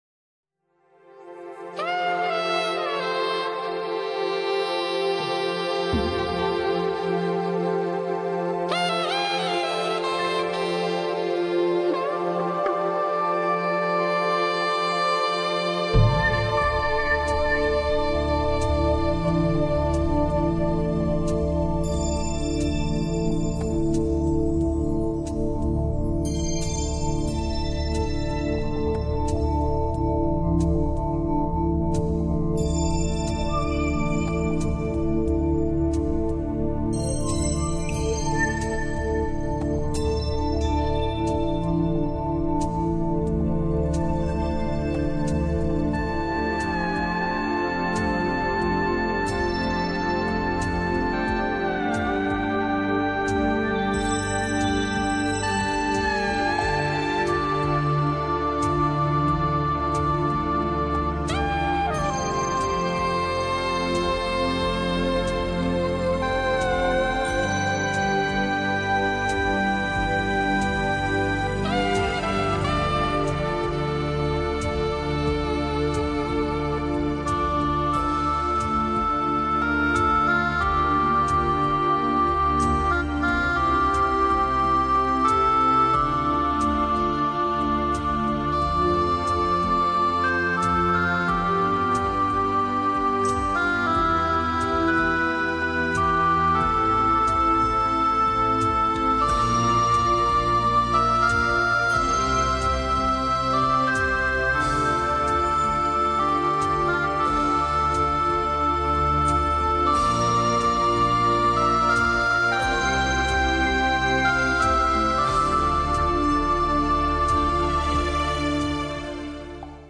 萨克斯风吹出浪漫又随性的旋律，随即在钢琴演奏中，思绪渐渐沉静。